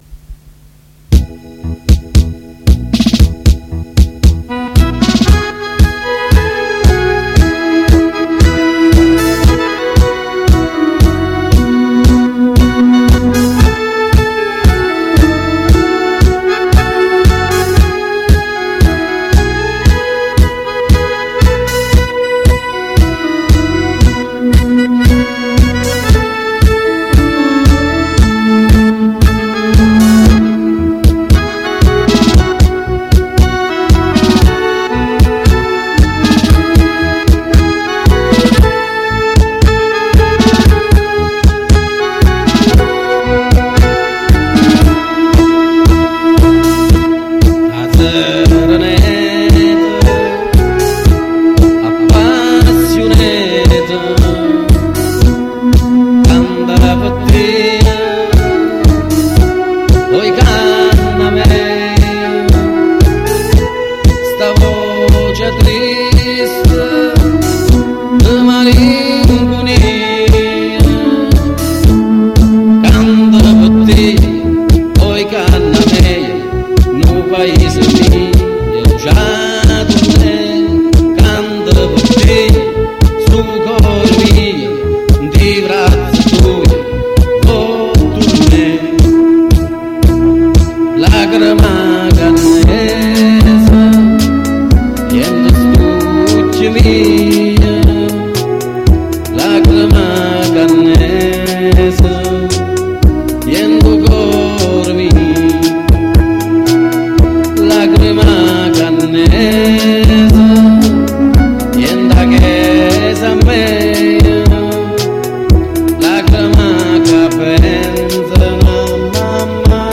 in dialetto cannese